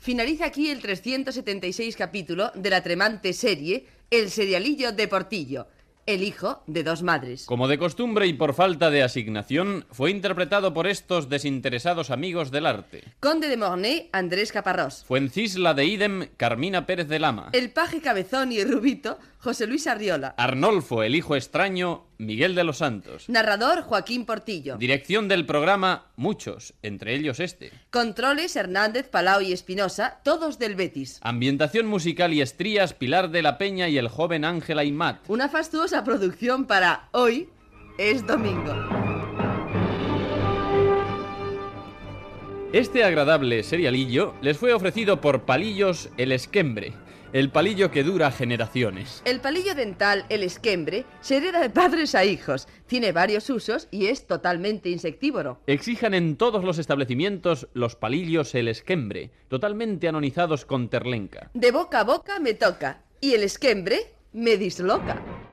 Repartiment i publicitat fictícia de "Palillos El Esquembre"